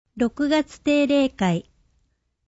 なお、この音声は「音訳グループまつさか＜外部リンク＞」の皆さんの協力で作成しています。